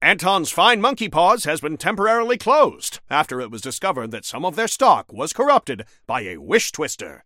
Newscaster_headline_70.mp3